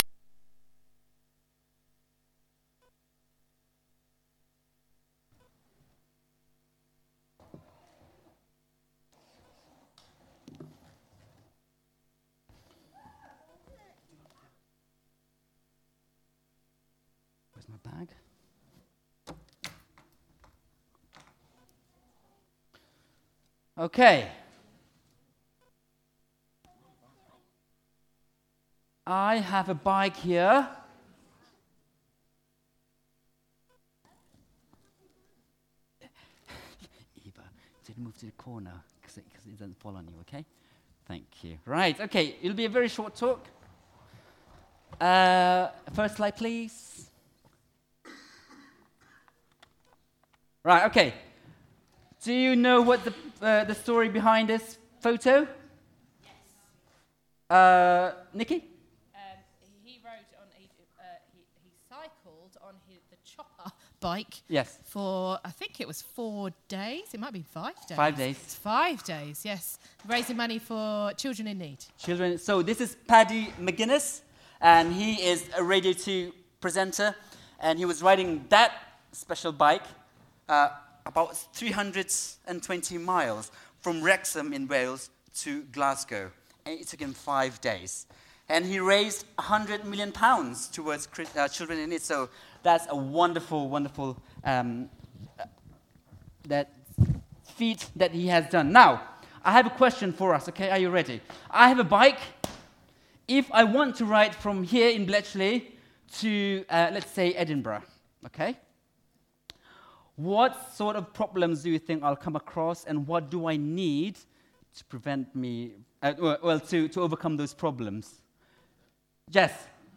Spurgeon Baptist Church - Nativity Service (Advent 1)